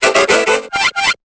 Cri de Chrysapile dans Pokémon Épée et Bouclier.